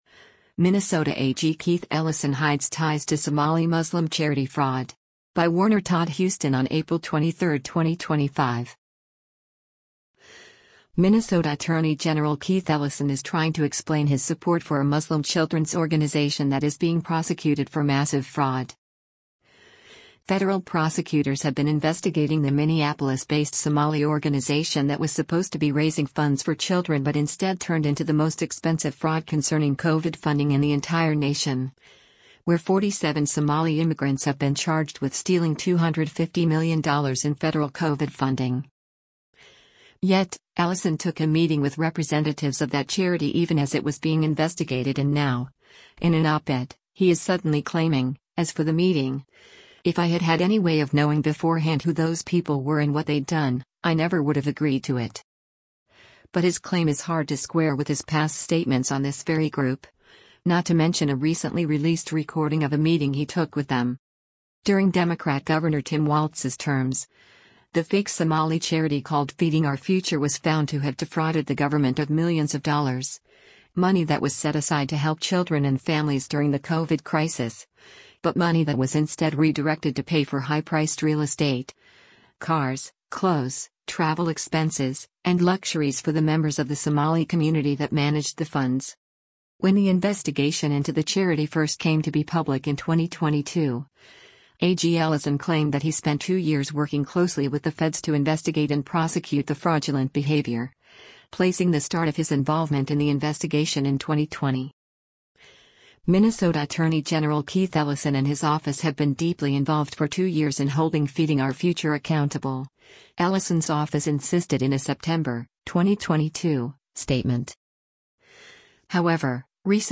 But his claim is hard to square with his past statements on this very group, not to mention a recently released recording of a meeting he took with them.
In the recording of the meeting with the Feeding Our Future representatives, Ellison seems to treat the group as a legitimate organization, does not acknowledge that they are under federal investigation, says he is there to “help” them, and even hints that if he speaks to his office, he could get that investigation dropped.